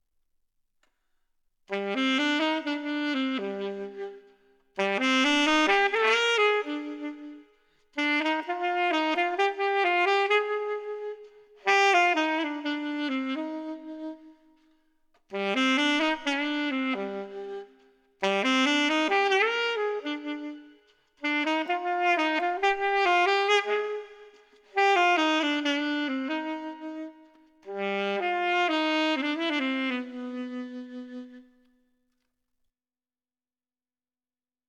Запись саксофона
Альт и баритон.